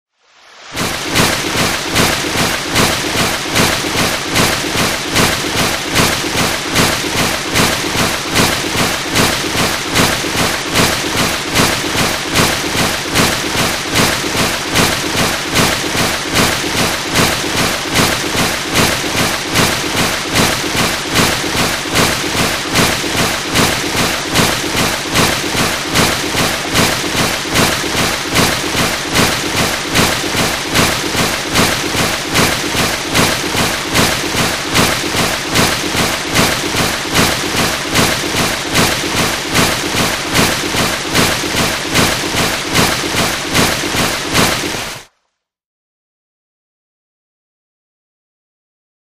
Water, Splashes | Sneak On The Lot